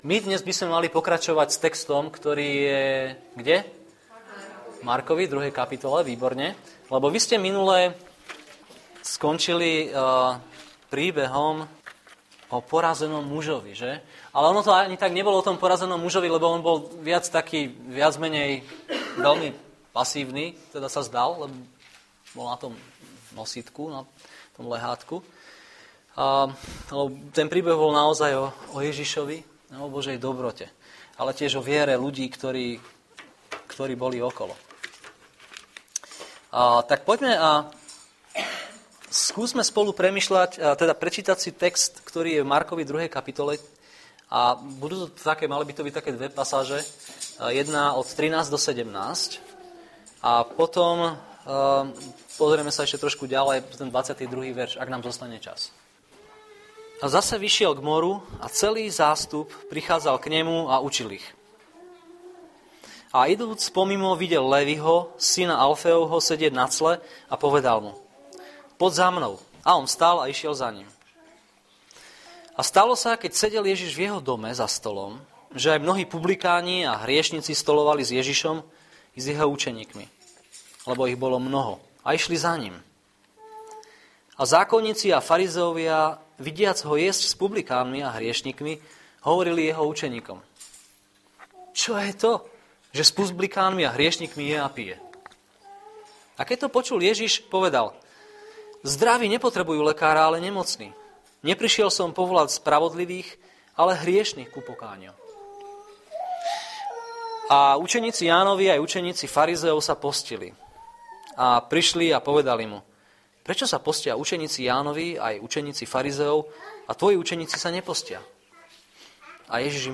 Nahrávka kázne Kresťanského centra Nový začiatok z 23. januára 2011